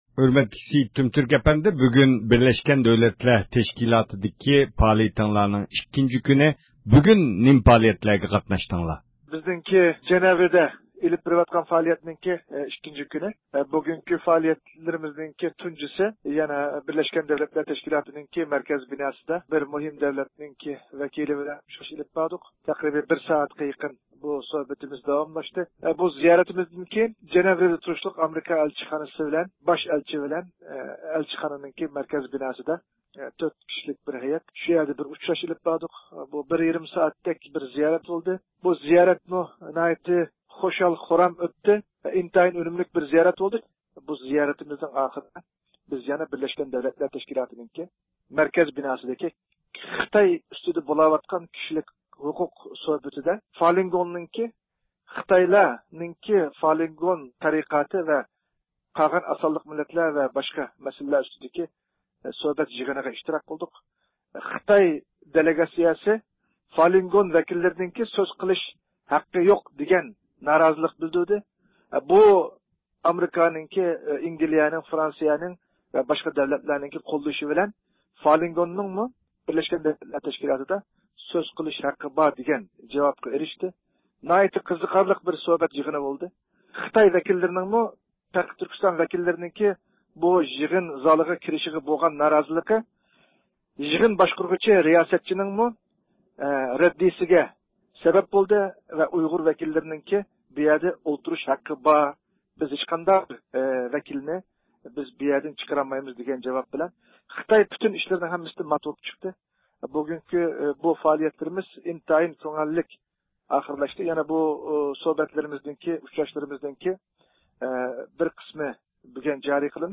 بىز بۇ ھەقتە تېخىمۇ تەپسىلىي مەلۇمات ئېلىش ئۈچۈن ئۇلار بىلەن تېلېفون سۆھبىتى ئېلىپ باردۇق.